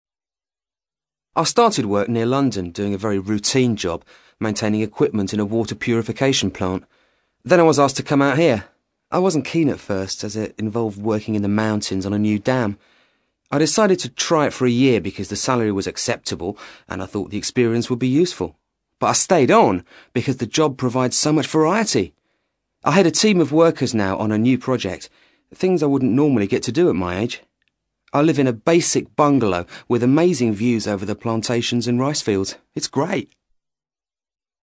ACTIVITY 112: You will hear five short extracts in which British people are talking about living abroad.